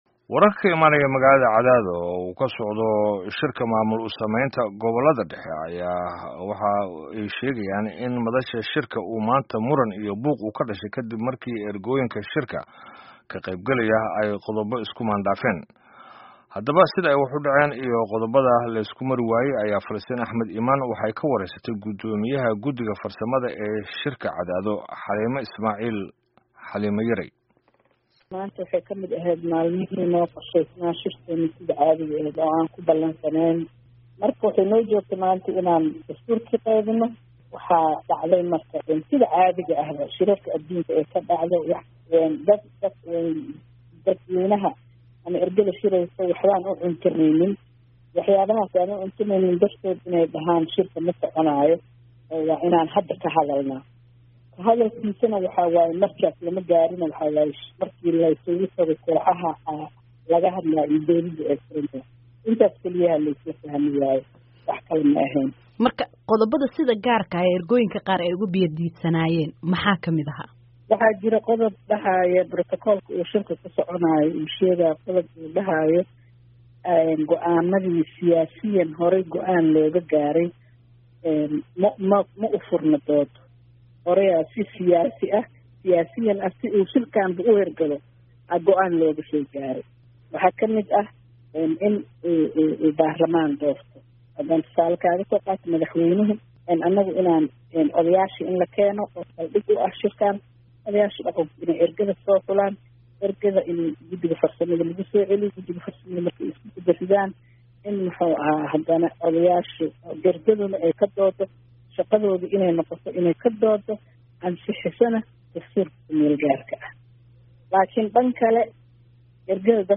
“Waxyaabaha muranka dhaliyay waxaa ka mid ah in ergada ay shaqadooda noqoto kaliya ka doodista iyo Ansixinta Dastuurka, balse xubnaha qaar ka mid ah ayaa ama musharaxiinta ayaa qof walba wuxuu doonayaa inay wax walba noqdaan sida uu wax u doonayo iyo ergada oo ku doodaysa inay iyagu doortaan madaxweynaha,” ayay tiri Xaliimo Yarey oo VOA-da u warrramaysay.